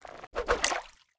throw.wav